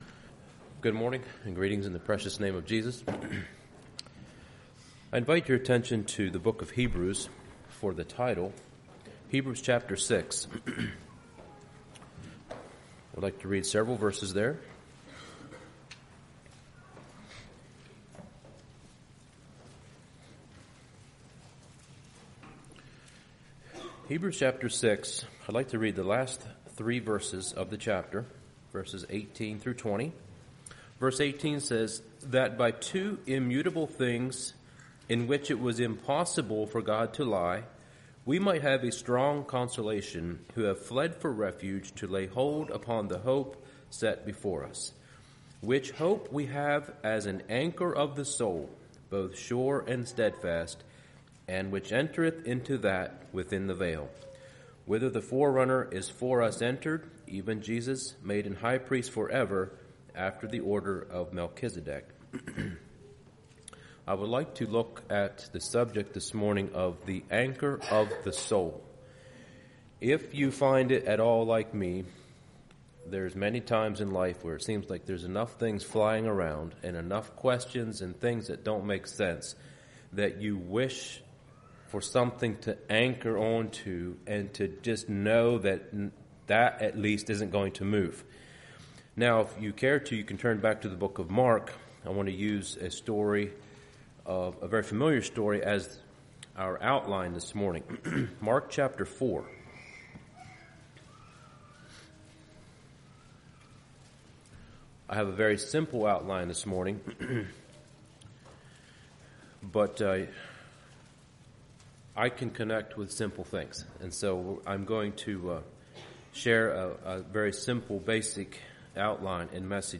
Sermons
Pensacola | Bible Conference 2024